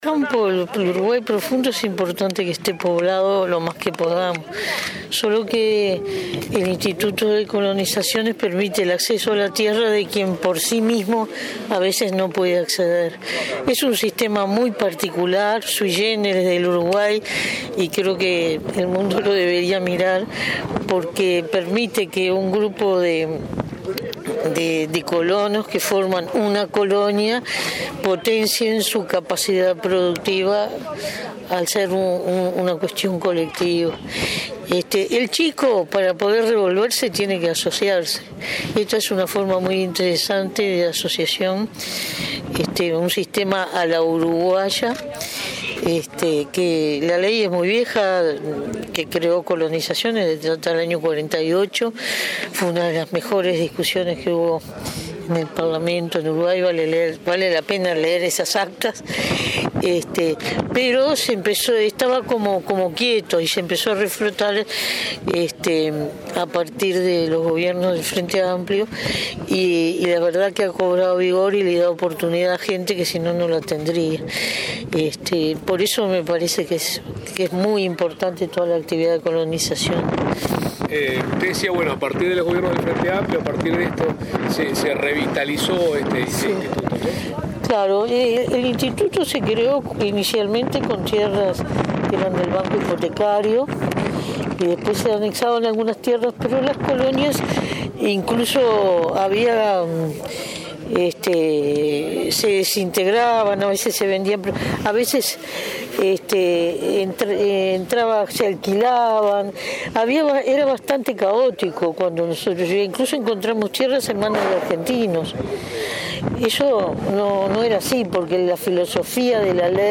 El Instituto Nacional de Colonización inauguró, este jueves 23 en Tacuarembó, la colonia Héctor Gutiérrez Ruiz, con 1.039 hectáreas que permiten a 12 familias de pequeños productores y asalariados rurales acceder a tierra en dos emprendimientos ganaderos. La vicepresidenta Lucia Topolansky destacó que desde 2005 se trabaja para que accedan a la tierra las personas que más lo necesitan.